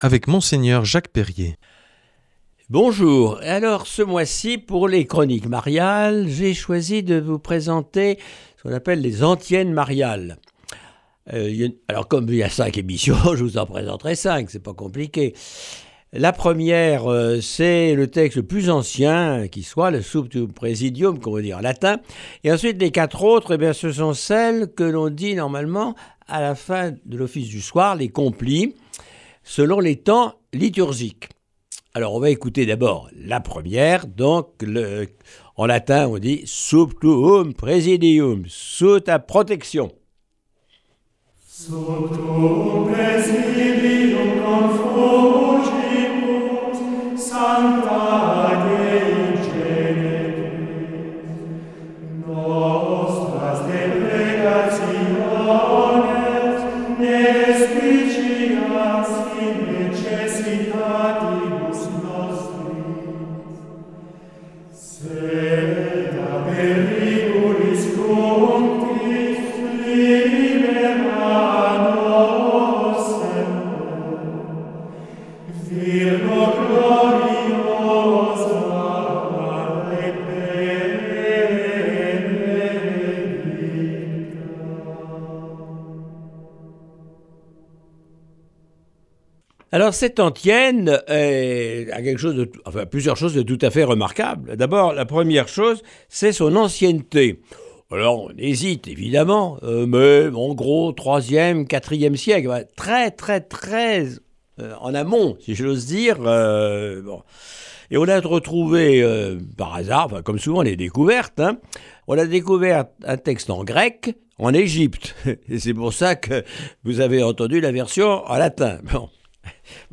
Cette semaine, Mgr Jacques Perrier nous propose des méditations sur des antiennes mariales. Aujourd’hui : Sub Tuum Praesidium.